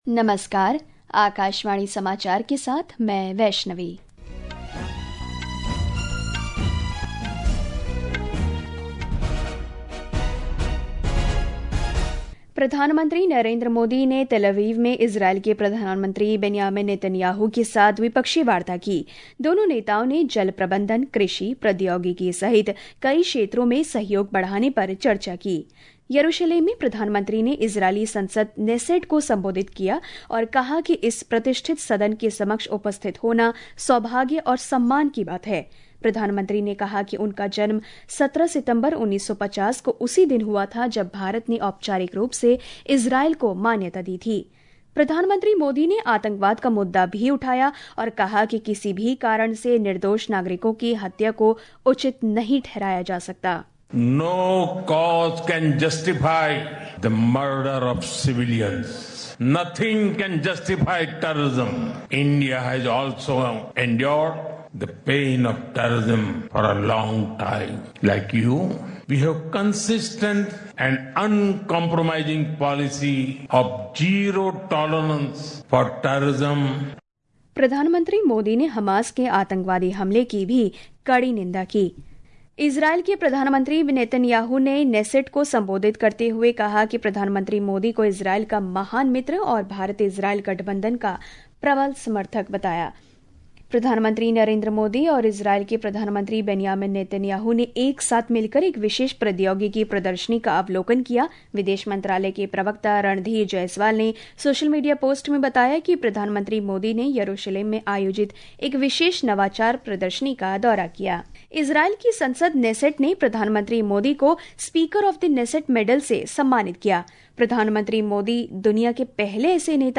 प्रति घंटा समाचार